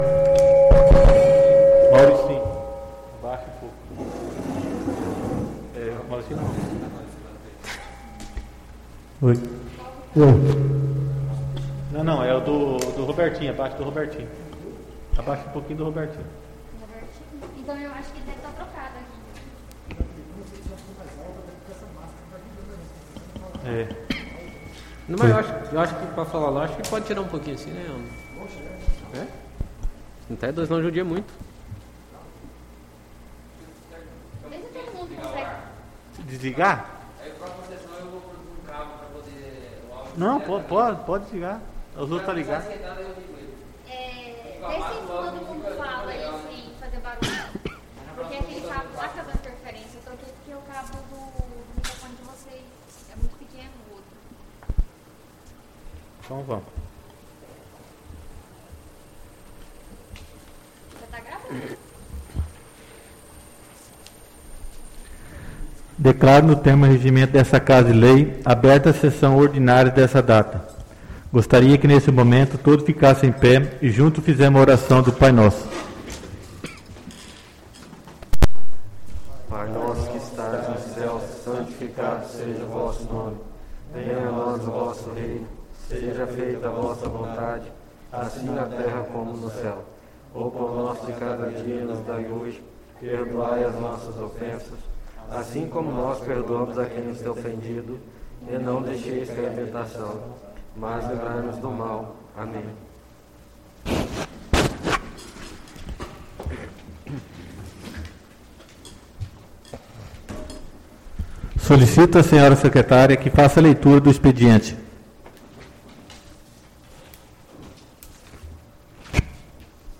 SESSÃO ORDINÁRIA DO DIA 07/07/2020